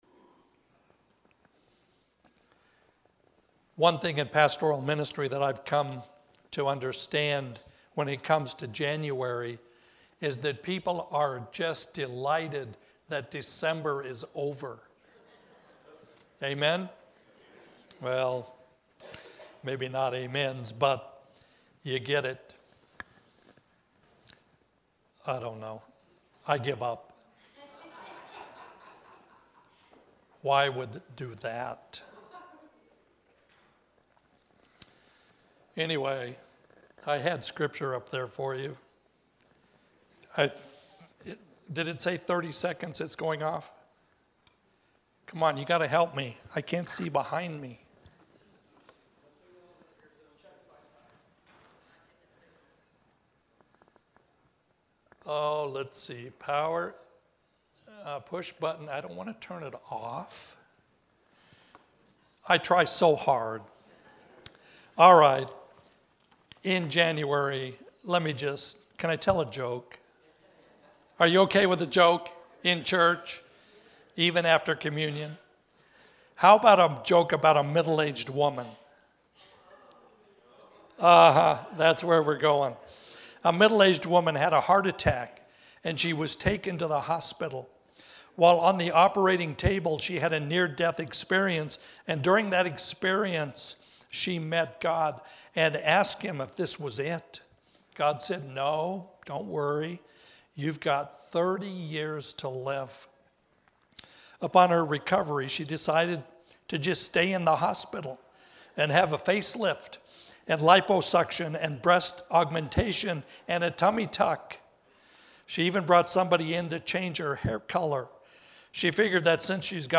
Sermon-Jan-14-2024.mp3